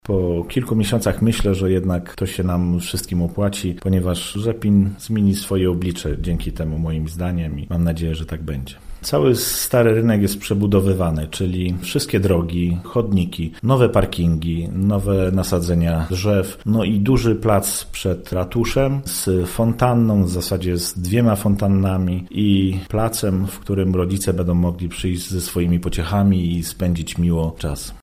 – Teraz ten obszar wygląda jak pole bitewne, ale za kilka miesięcy mieszkańcy placu Ratuszowego nie poznają – mówi Sławomir Dudzis, burmistrz Rzepina: